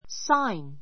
sáin サ イン （ ⦣ g は発音しない）